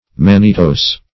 Search Result for " mannitose" : The Collaborative International Dictionary of English v.0.48: Mannitose \Man"ni*tose`\, n. (Chem.) A variety of sugar obtained by the partial oxidation of mannite, and closely resembling levulose.